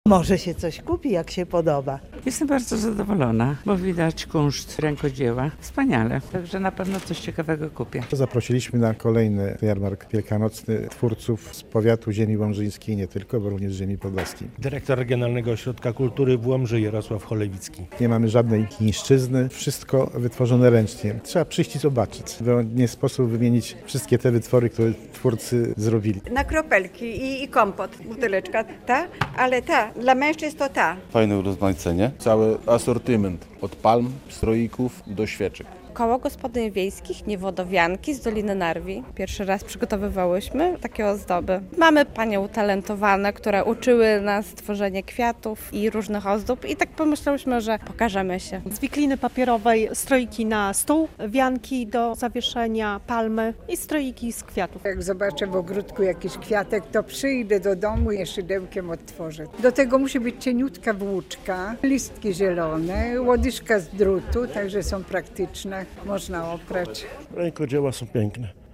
Wyjątkowe artystyczne ozdoby w Łomży. Rozpoczął się Jarmark Wielkanocny